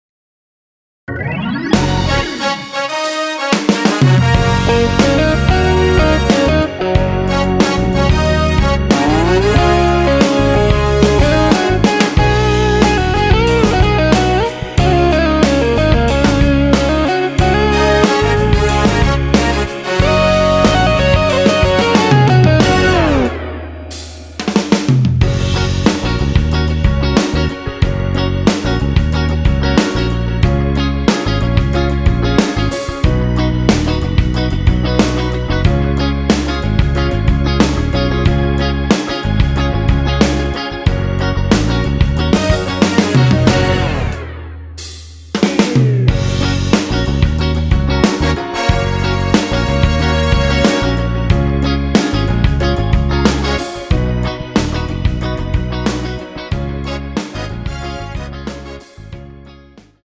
원키에서(-1)내린 MR입니다.
Gb
앞부분30초, 뒷부분30초씩 편집해서 올려 드리고 있습니다.